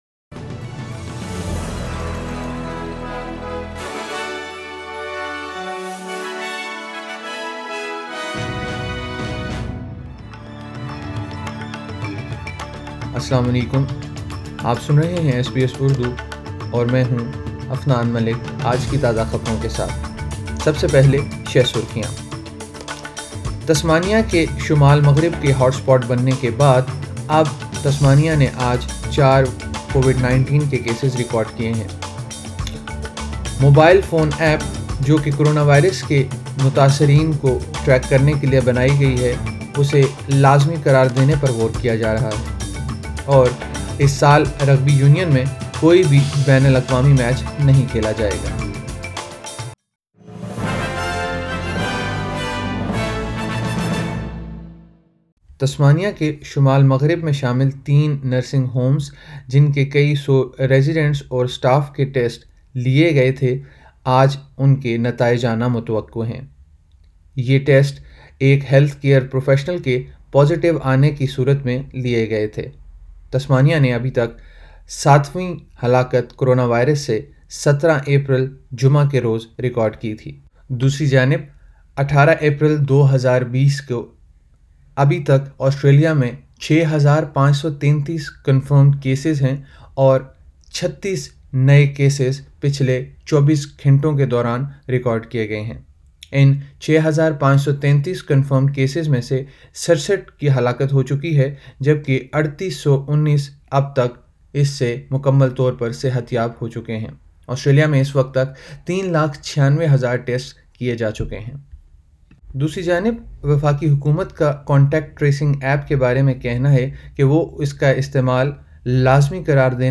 sbs_news_sat_18_apr.mp3